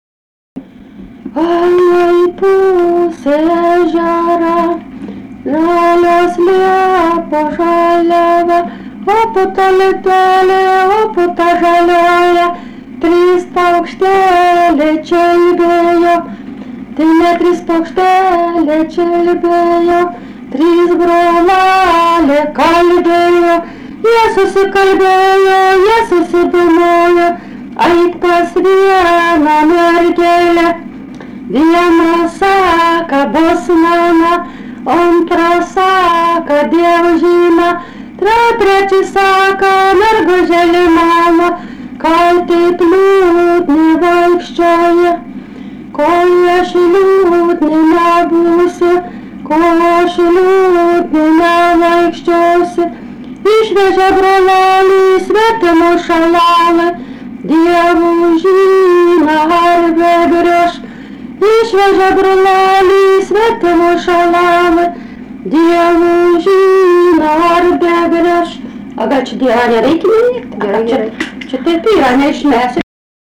daina
Tribuliškiai
vokalinis